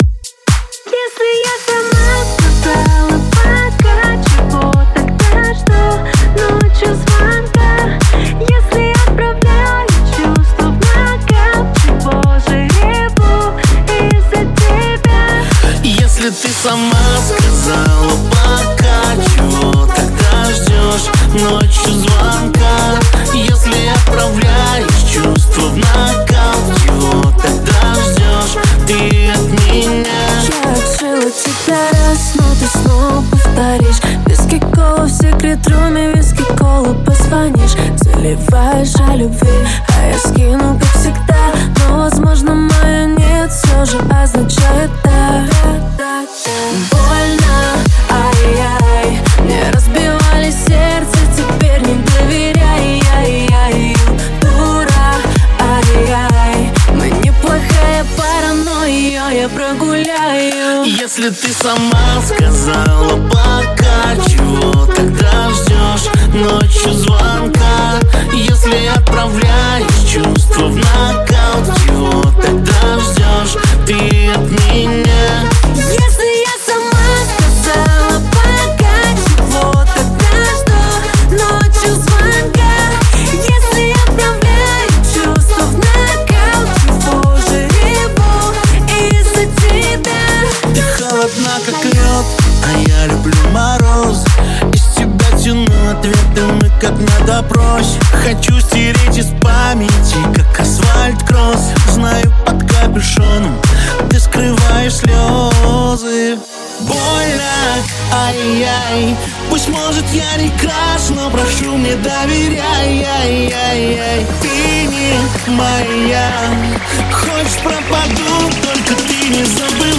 Электроника
Жанр: Жанры / Электроника